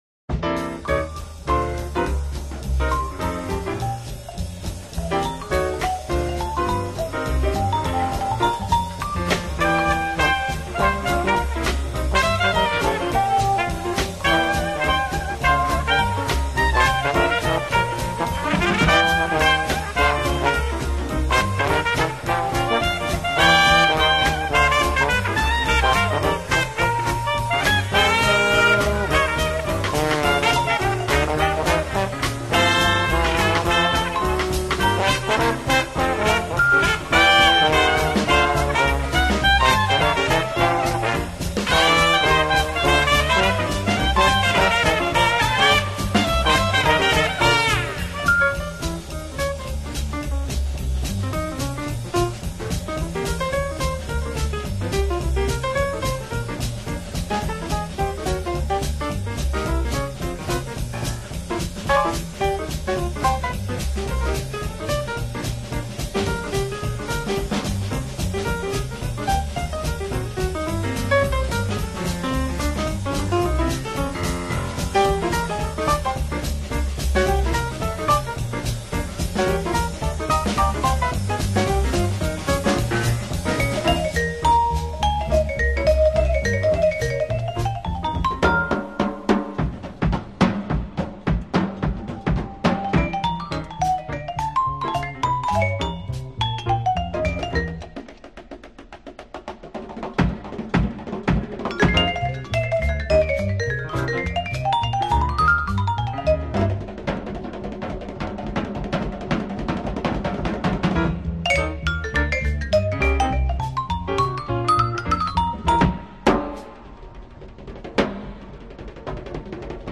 Orchestre de Jazz - Big band